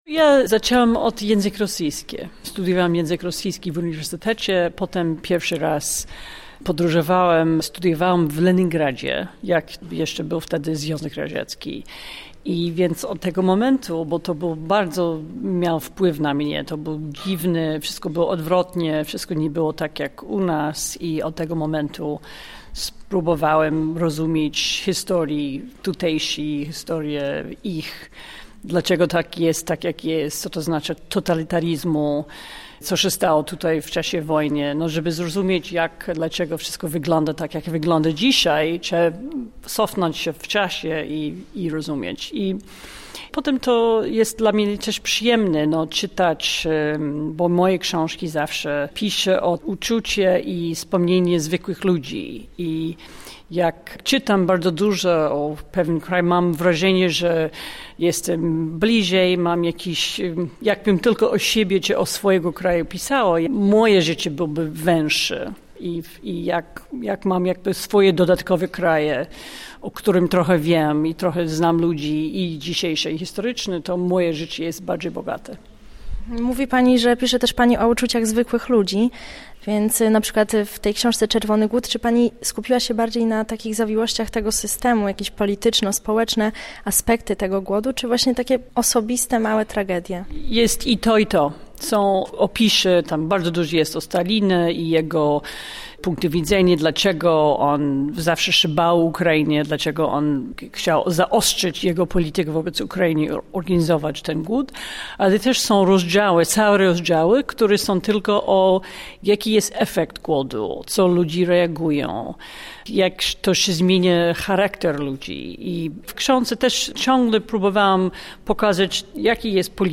która rozmawiała z dziennikarką